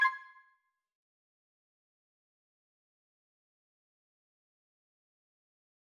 back_style_4_004.wav